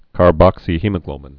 (kär-bŏksē-hēmə-glōbĭn)